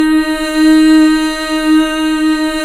Index of /90_sSampleCDs/Club-50 - Foundations Roland/VOX_xFemale Ooz/VOX_xFm Ooz 1 M